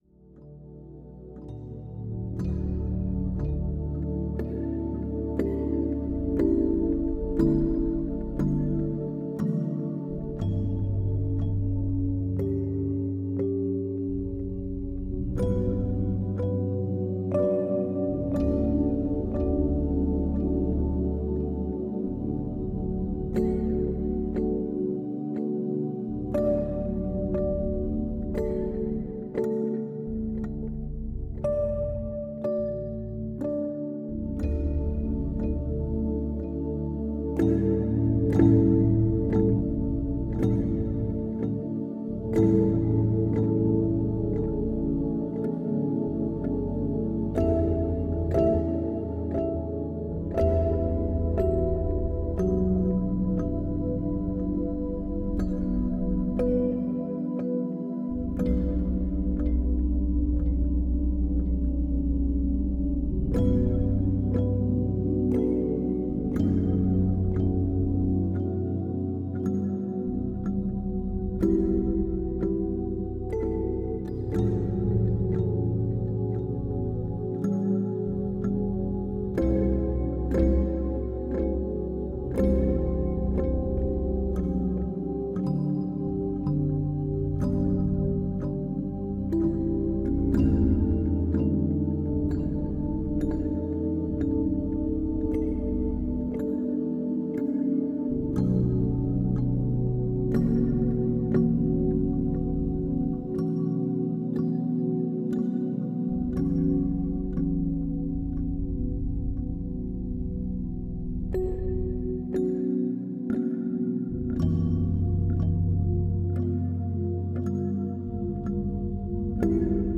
Успокаивающая музыка для крепкого сна